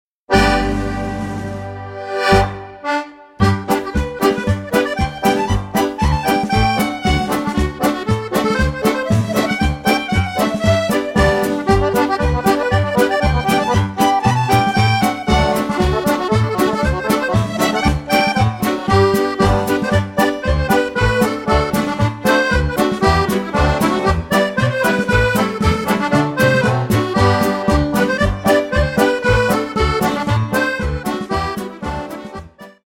5 x 40 Reel